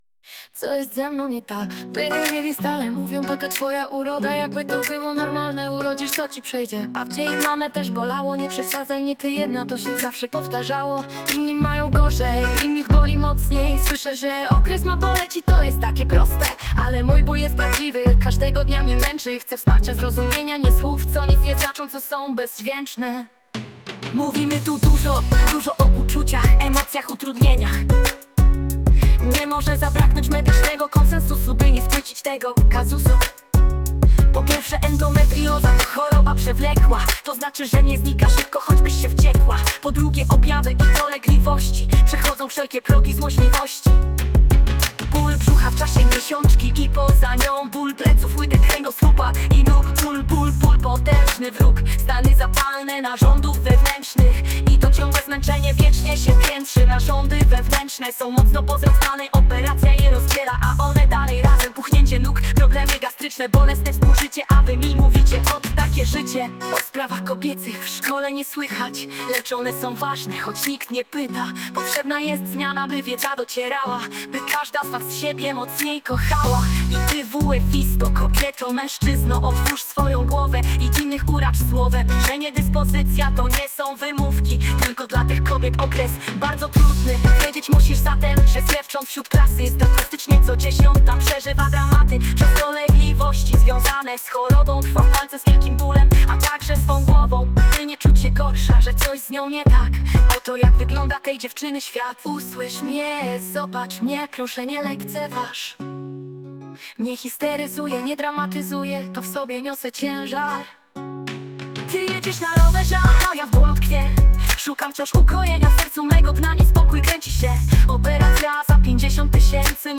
Treść utworu opowiada o tym, czym jest choroba i jak można wspierać osoby chorujące. Link do piosenki, którą do naszego studenckiego tekstu zaśpiewała sztuczna inteligencja znajdziecie